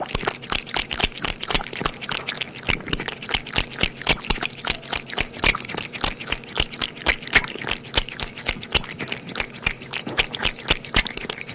Звук: кошечка кушает молочко